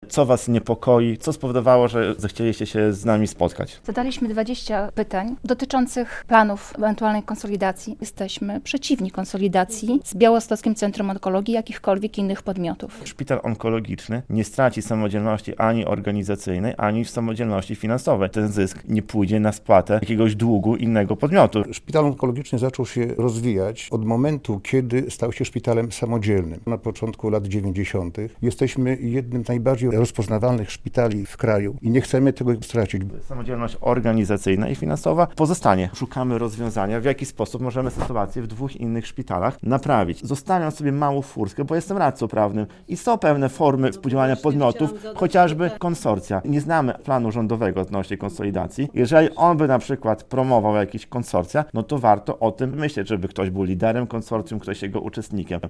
W piątek (28.02) o przyszłości ich placówki mówił marszałek Łukasz Prokorym.